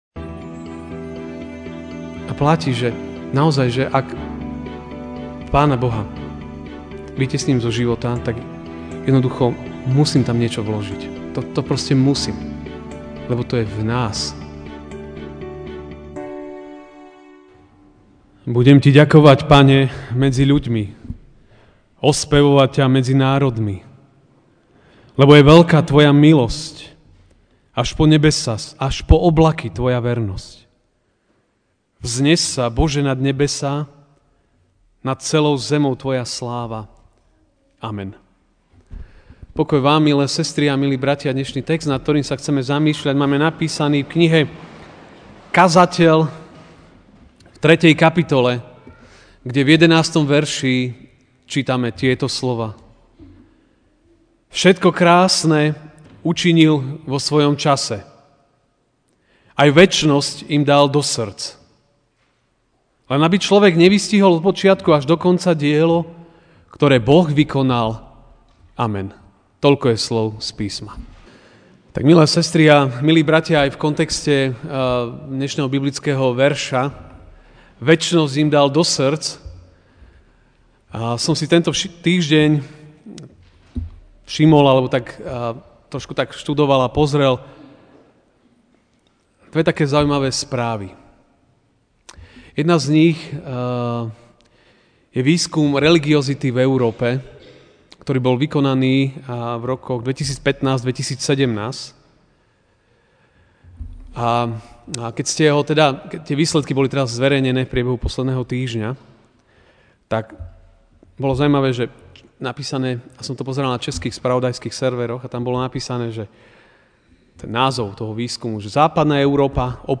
Ranná kázeň: Stvorení pre večnosť (Kaz 3, 11)Všetko krásne učinil vo svojom čase, ajvečnosť im dal do sŕdc, len aby človek nevystihol od počiatku až do koncadielo, ktoré Boh vykonal.